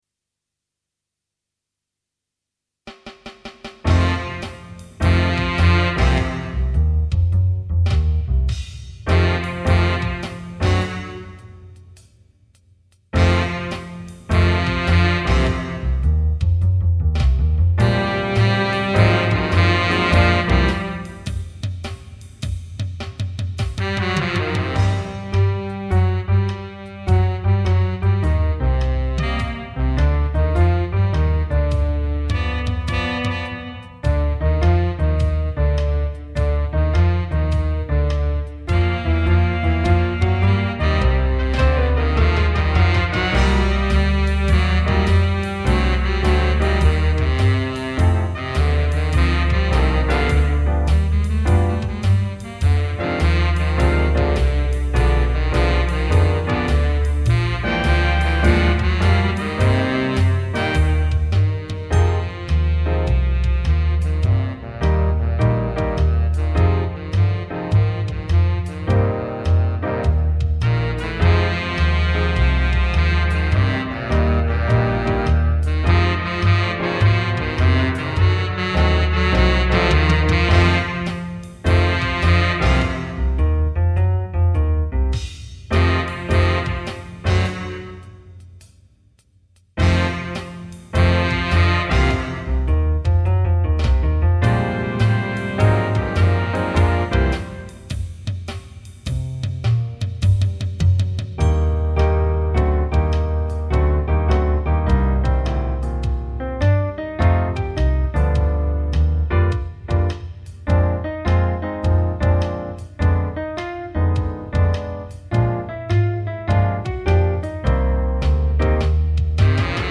minus Instrument 4